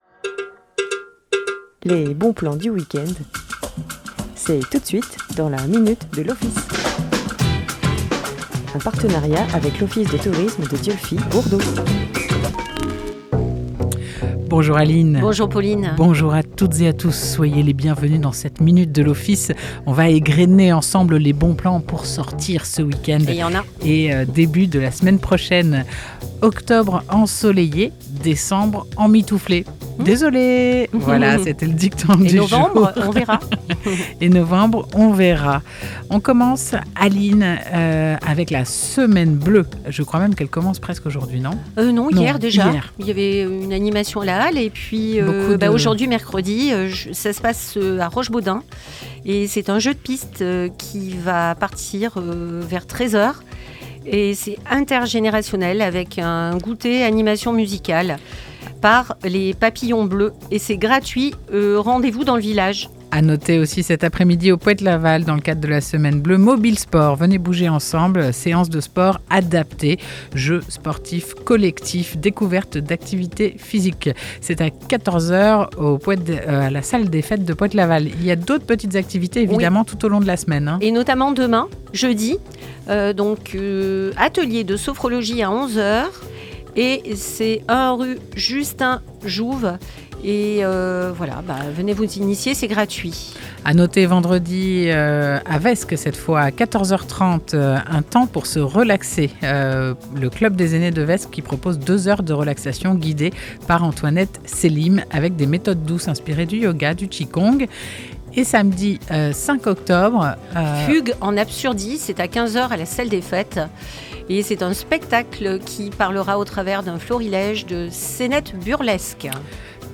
Minute de l'Office Chaque mercredi à 9h30 en direct